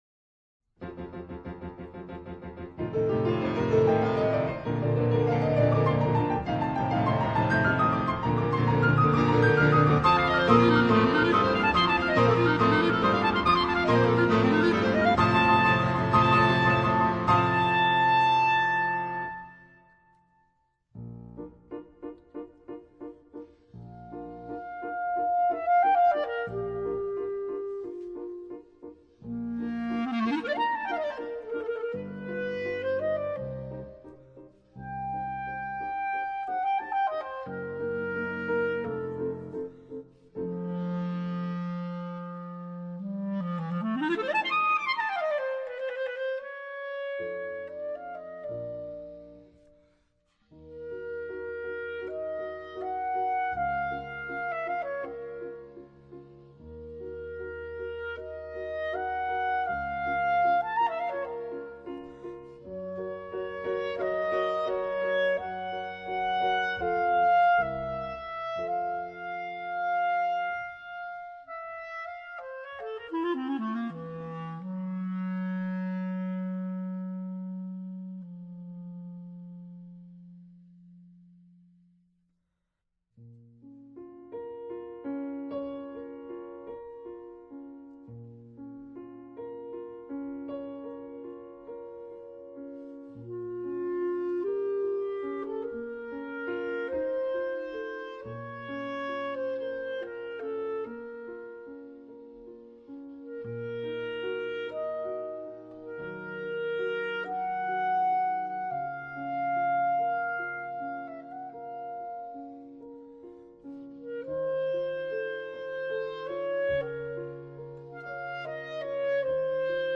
Pianoforte
clarinetto